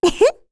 Rehartna-Vox-Laugh3_kr.wav